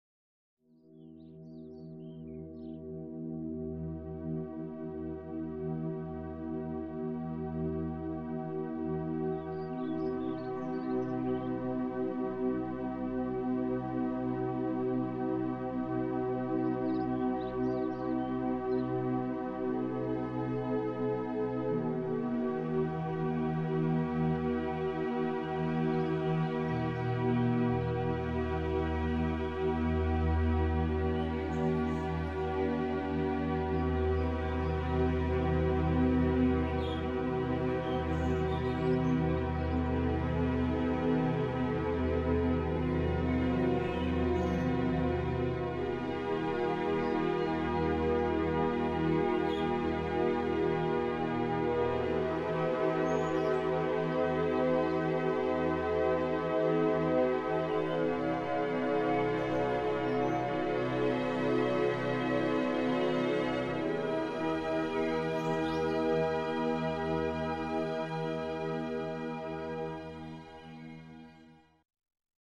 Solfeggio Heilmusik 639 Hertz